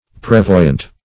Search Result for " prevoyant" : The Collaborative International Dictionary of English v.0.48: Prevoyant \Pre*voy"ant\, a. [F. pr['e]voyant.] Foreseeing; prescient.